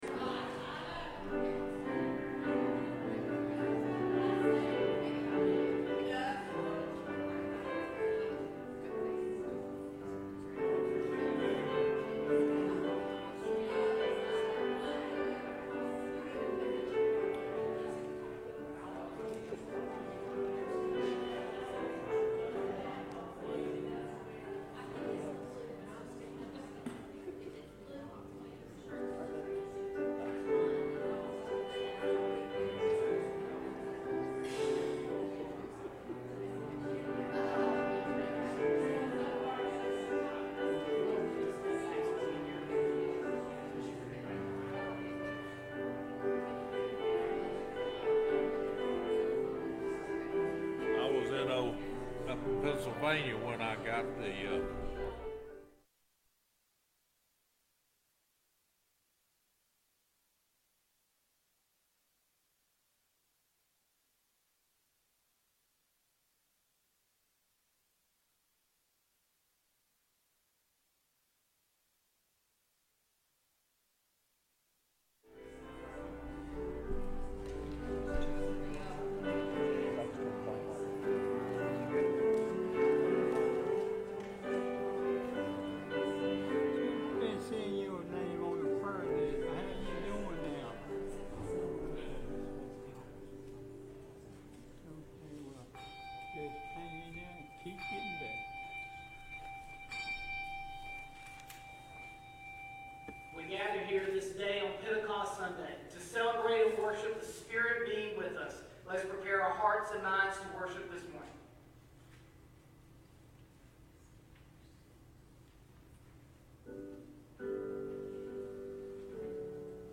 Passage: 2 Timothy 1:7, Philippians 3:13-14, Hebrews 12:1-3 Service Type: Morning « What are you Looking & Waiting For?